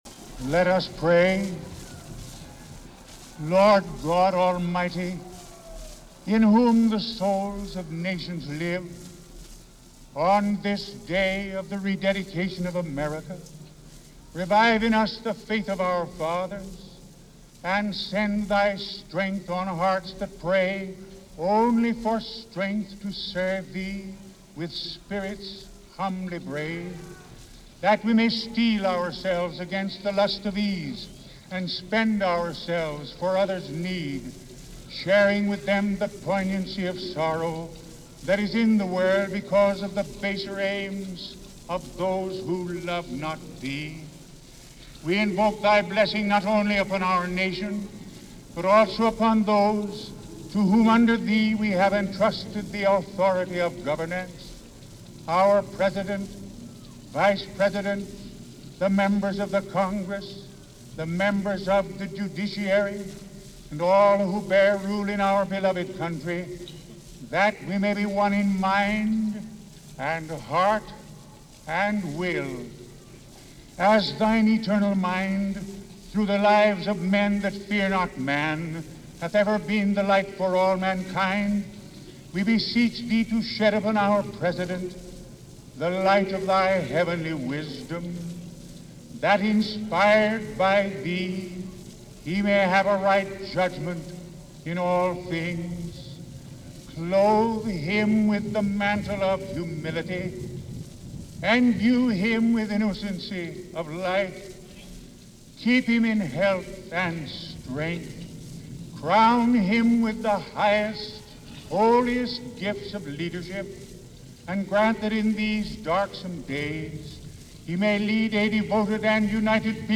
January 20, 1941 - Franklin D. Roosevelt, 3rd Inauguration - FDR gives his address for 3rd term as President - Past Daily Reference Room.
FDR-Inauguration-1941.mp3